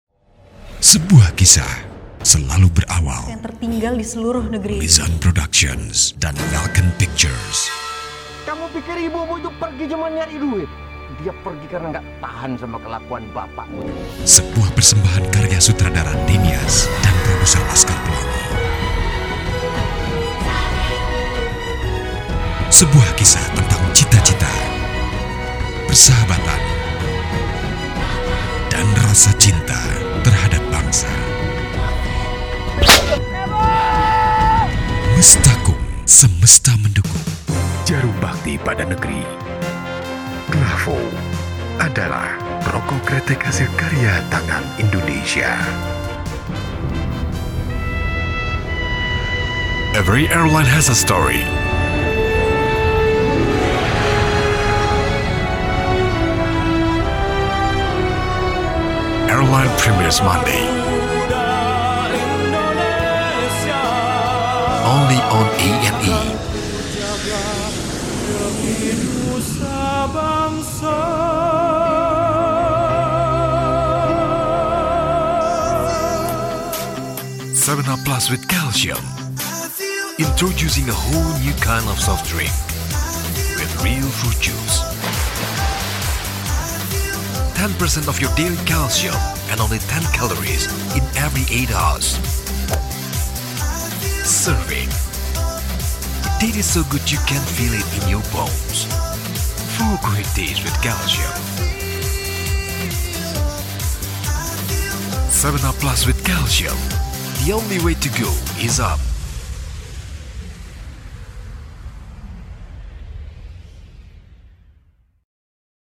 WARM VOICE & DRAMATIC
Sprechprobe: Werbung (Muttersprache):